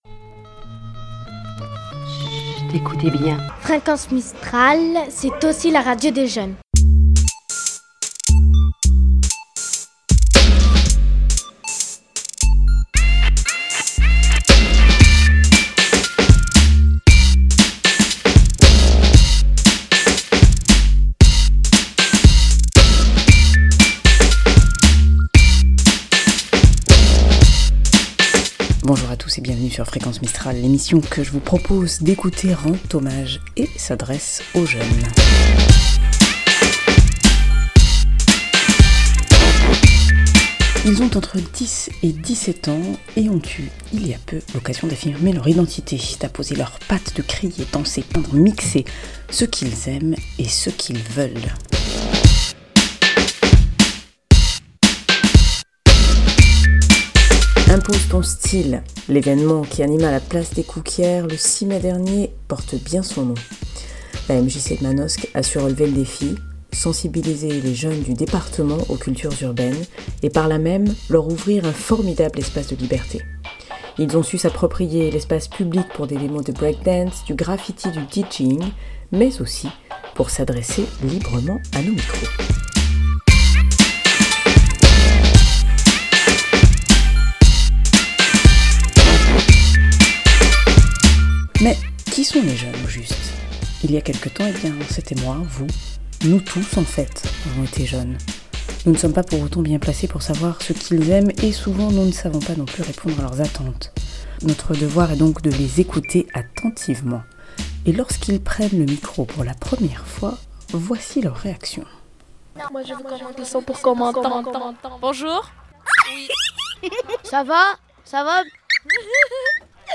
La MJC de Manosque a su relever le défi: sensibiliser les jeunes du département aux cultures urbaines en leur ouvrant un formidable espace de liberté! Ils ont su s'approprier l'espace public pour des démonstrations de break dance, du graffiti, du DJing, mais aussi pour s'adresser librement à nos micros.